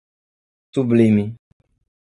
Prononcé comme (IPA)
/suˈblĩ.mi/